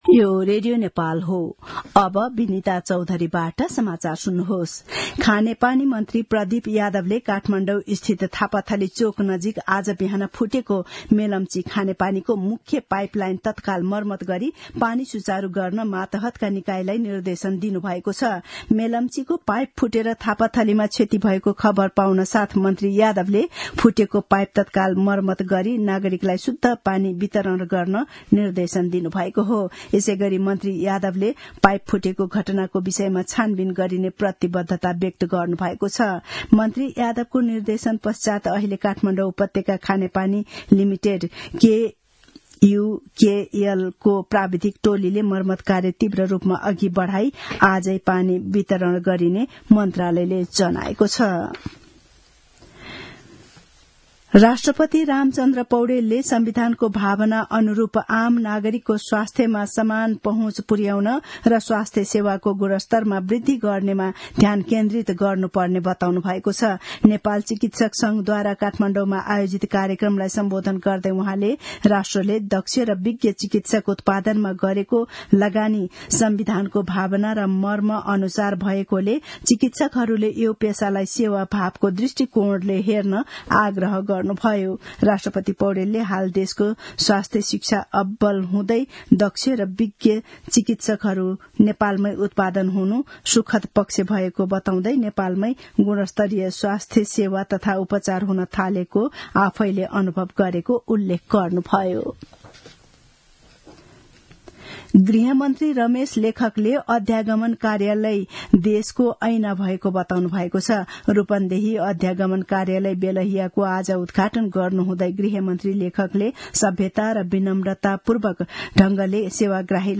दिउँसो १ बजेको नेपाली समाचार : २१ फागुन , २०८१
1-pm-news-1-1.mp3